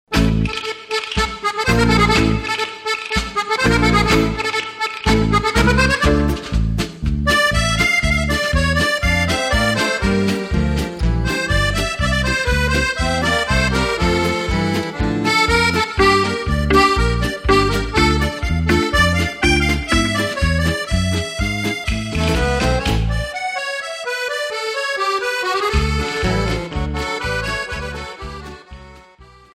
Ballabili Sudamericani
Paso doble